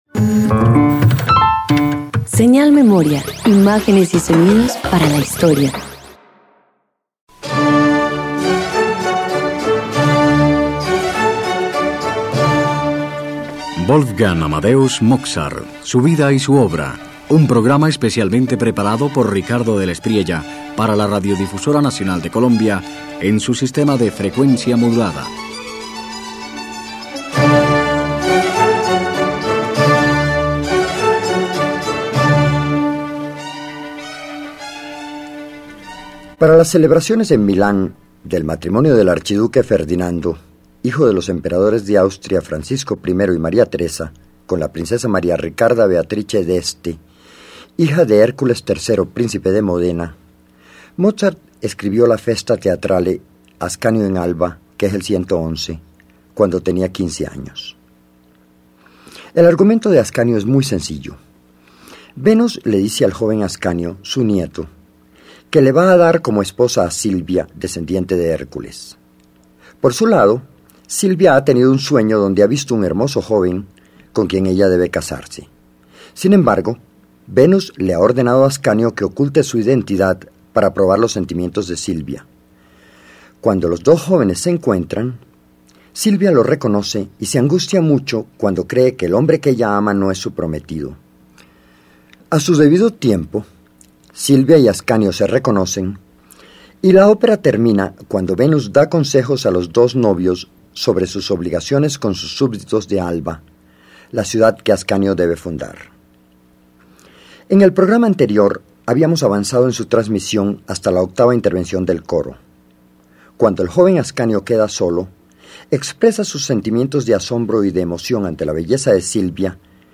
Cada aria ejerce claridad: cuerdas que respiran, voces que se encuentran y un coro que sostiene la arquitectura de lo divino.